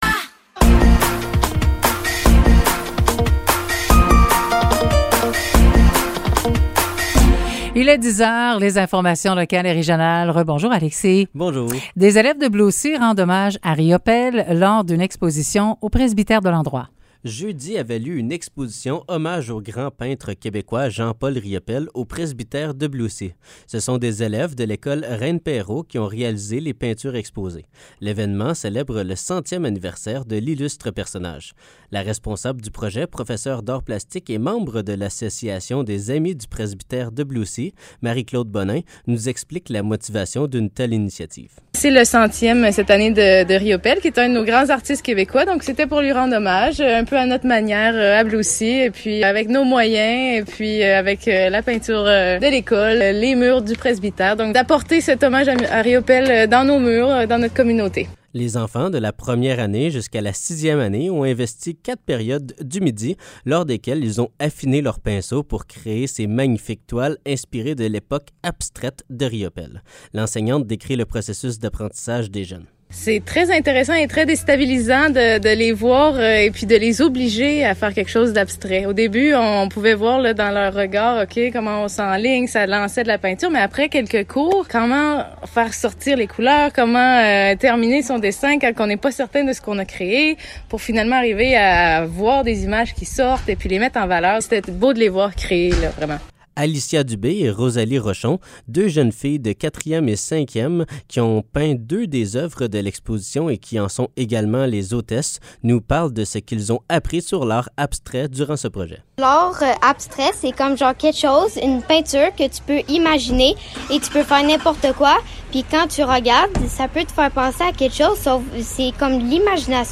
Nouvelles locales - 19 juin 2023 - 10 h